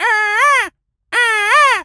bird_vulture_squawk_02.wav